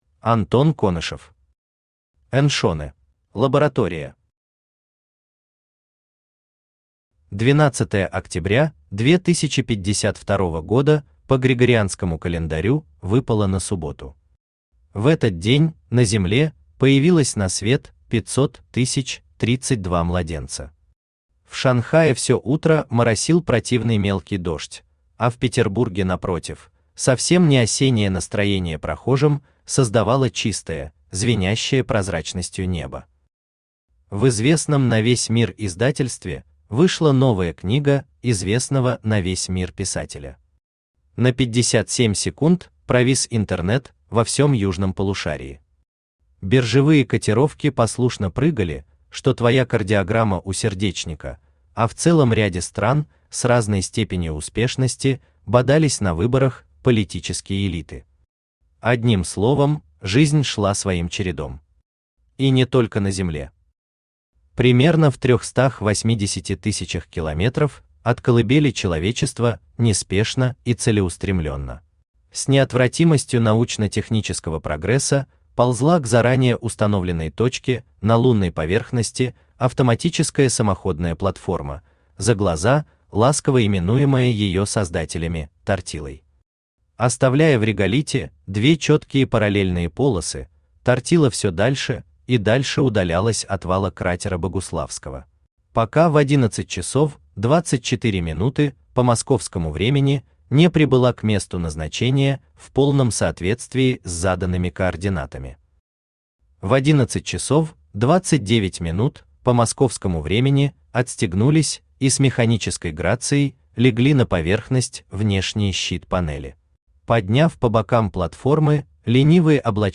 Аудиокнига Эншенэ | Библиотека аудиокниг
Aудиокнига Эншенэ Автор Антон Конышев Читает аудиокнигу Авточтец ЛитРес.